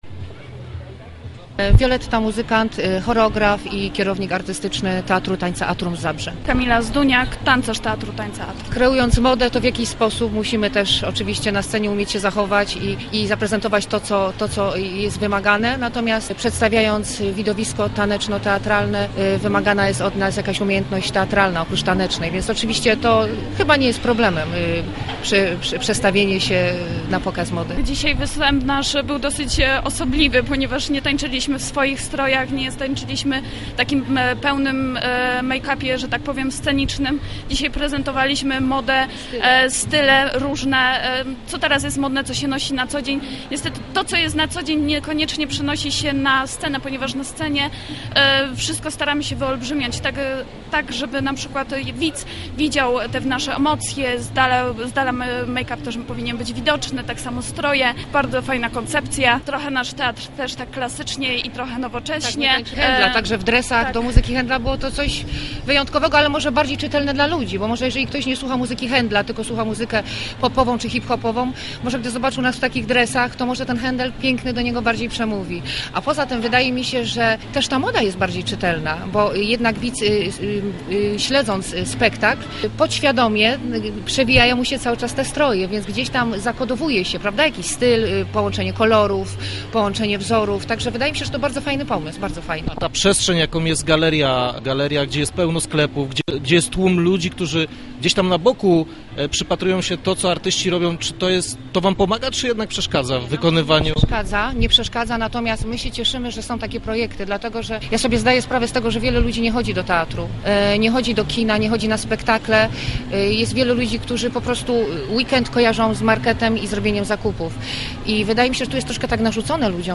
Materiał naszego reportera: